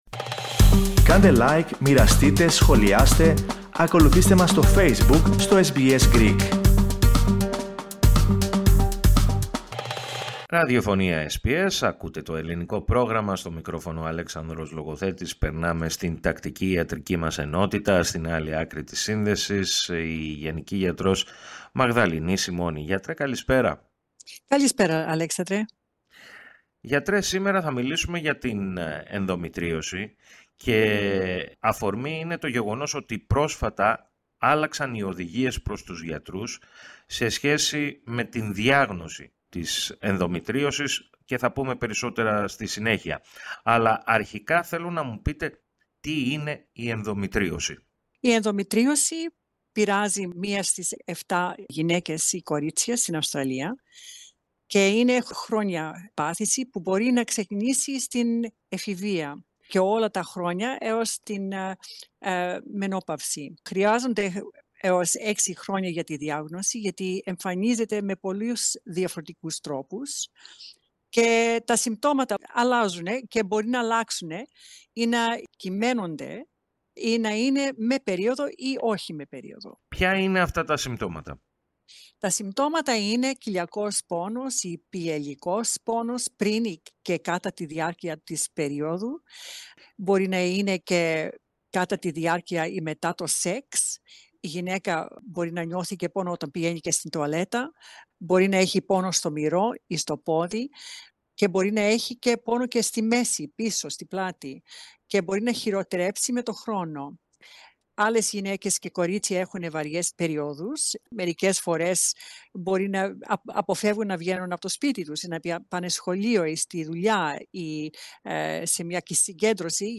η γενική γιατρός